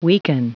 Prononciation du mot weaken en anglais (fichier audio)
weaken.wav